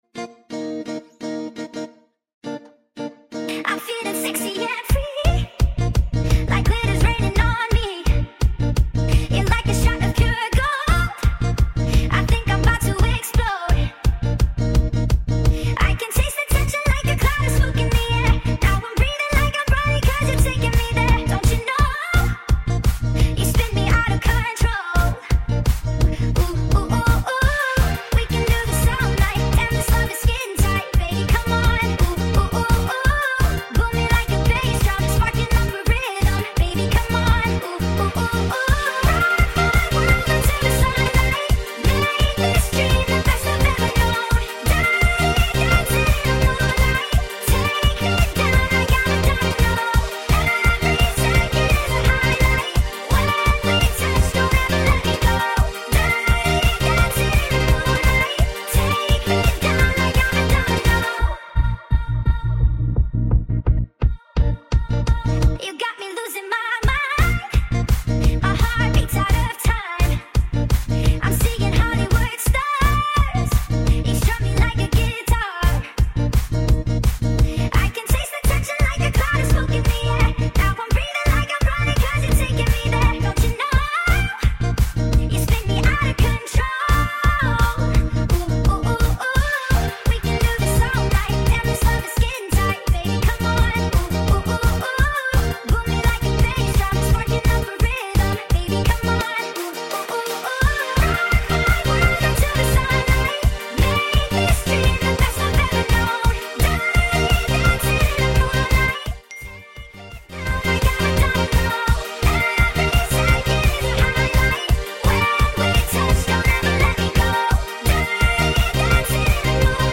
sped up!!